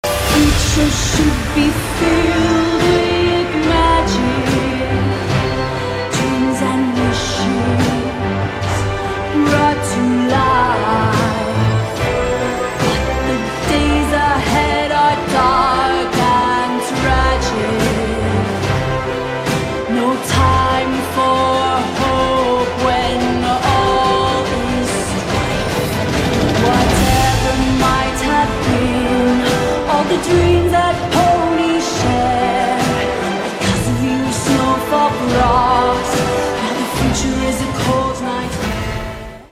• Качество: 320, Stereo
красивые
женский голос
ремикс
эпичные
детские
Ремикс на музыку из мультфильма